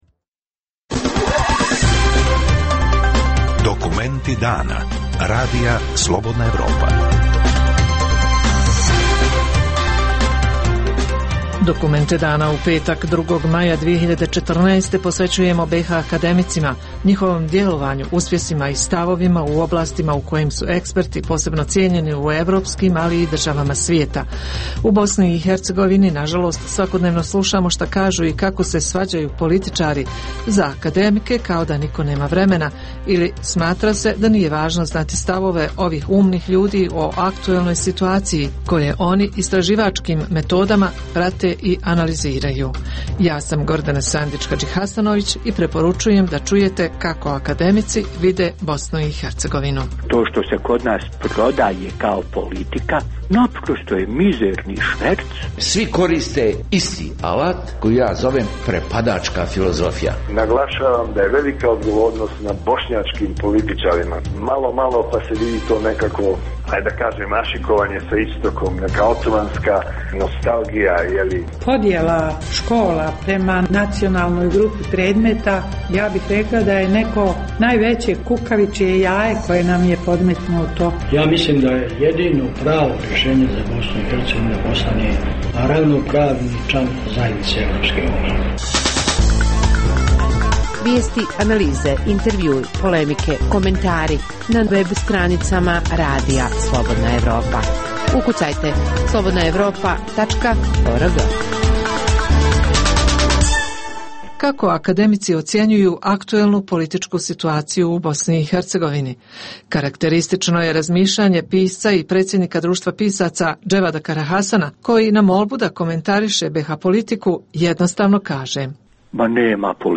U Dokumentima dana govore članovi Akademije nauka i umjetnosti BiH - o aktuelnoj situaciji u zemlji, o odnosu prema nauci i istraživačkom radu, o nekadašnjim privrednim gigantima i bogatoj naučnoj dijaspori u svijetu. Svoje stavove iznosi deset akadmika, koji stvaraju u različitim oblastima - od filozofije, pedagogije, fizike, ekonomije do mikorbiologije i neuropsihijatrije.